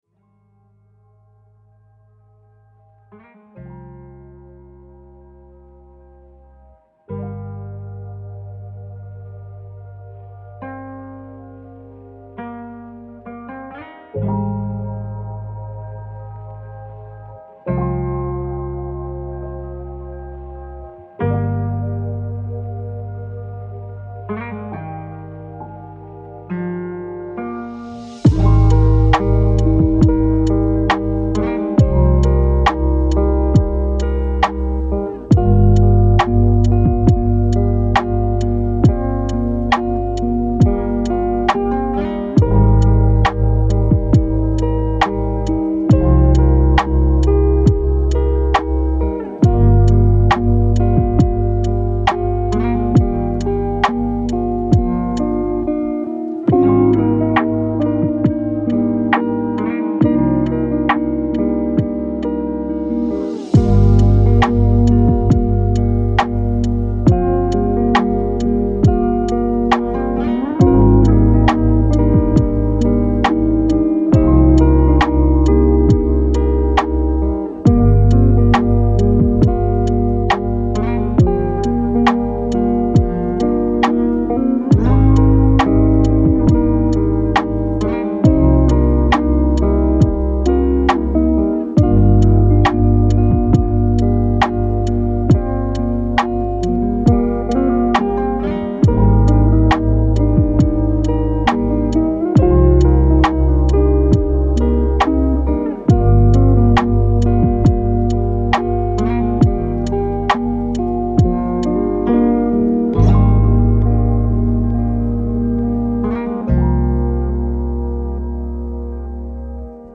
Sommeil 432 Hz : Repos Profond